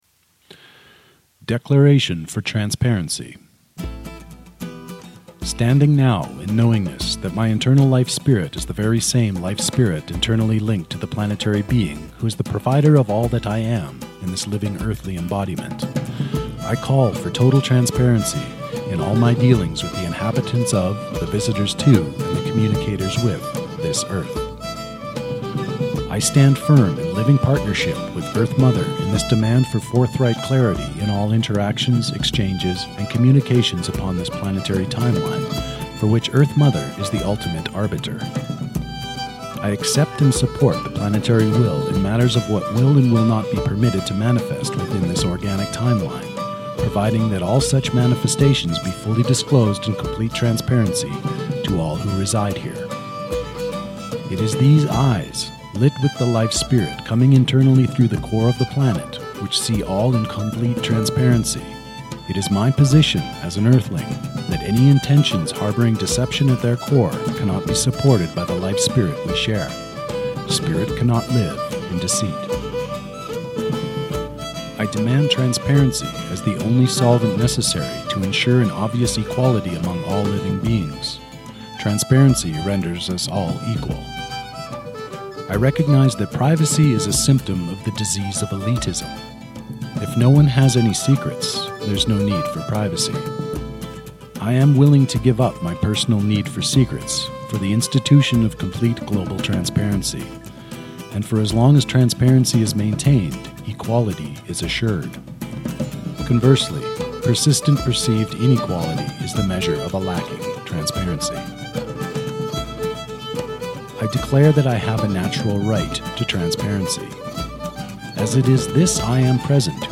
(Author Narration with musical accompaniment: The Road To Liskeard by Gaelic Storm)